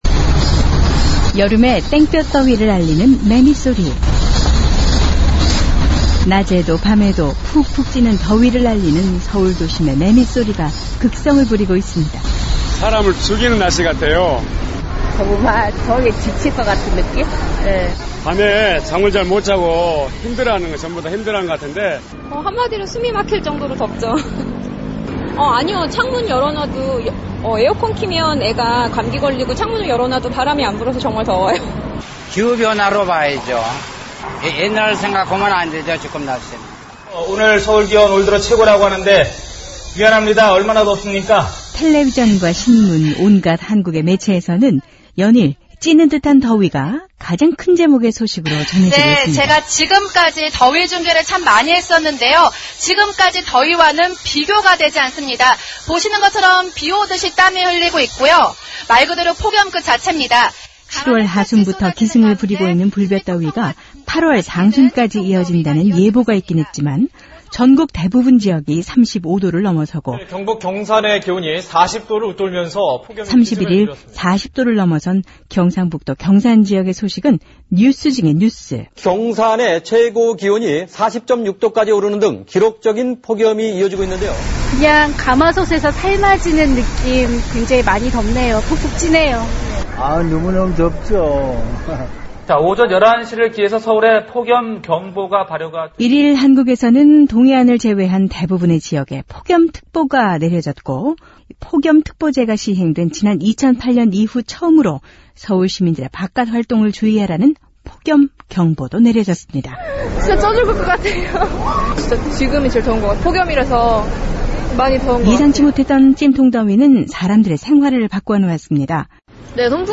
요즘 한국 날씨가 대단합니다. 37~38도를 오르내리는 것은 기본이고, 지난 31일 경상북도 경산지역은 40도를 넘어섰다고 하지요. 여름이 더운 것은 당연한 일이지만, 이렇게 더워도 되는 건가 하는 한숨이 절로 나올 정도라고 하는데요, 오늘은 불볕더위, 찜통더위를 지내고 있는 서울 사람들의 목소리를 담아봤습니다.